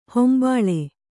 ♪ hombāḷe